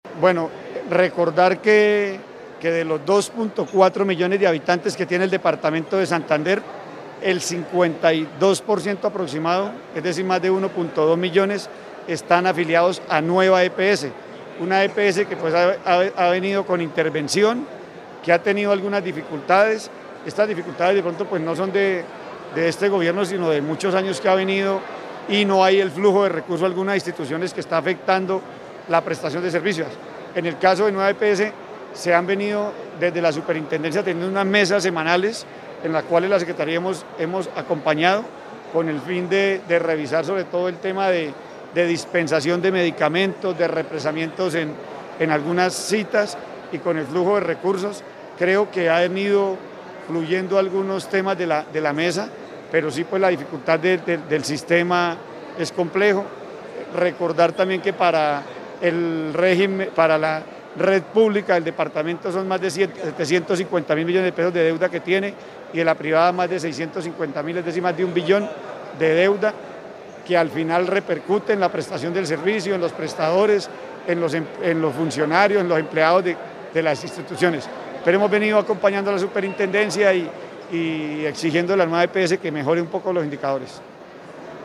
Edwin Prada, secretario de salud de Santander